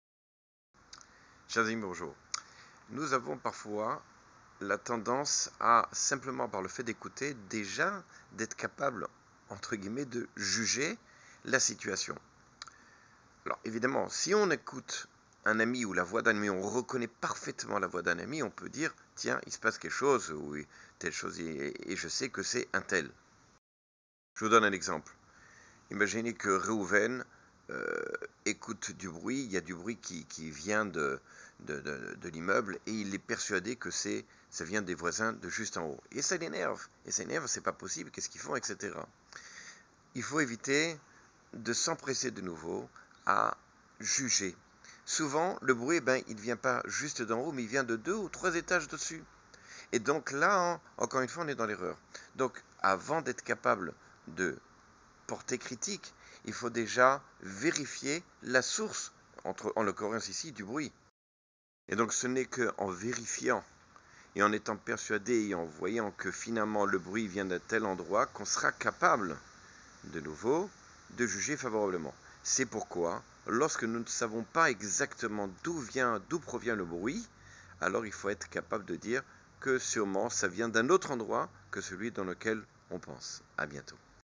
Cours sur les lois de juger favorablement.